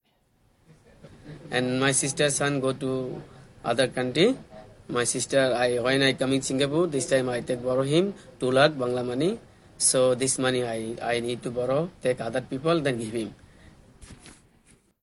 Here are some extracts from the debriefing interview.